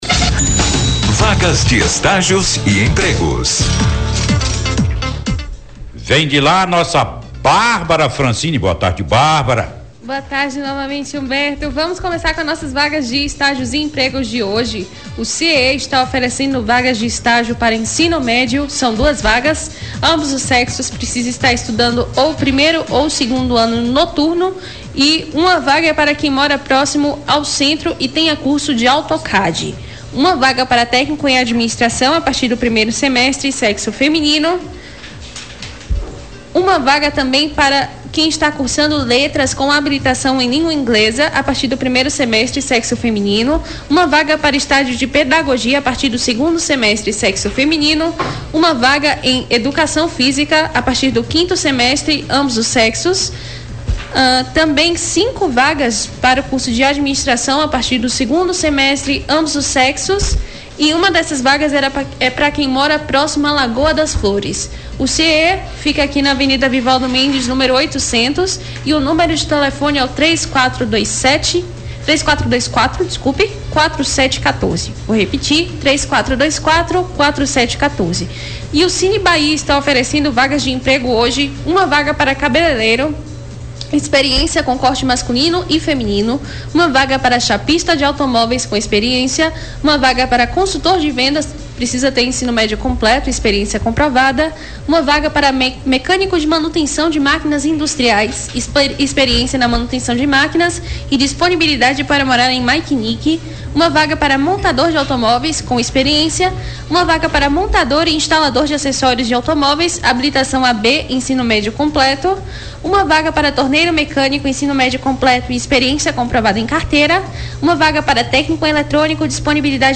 Na sonora, a repórter traz todos os destaques de empregos e estágios em Vitória da Conquista.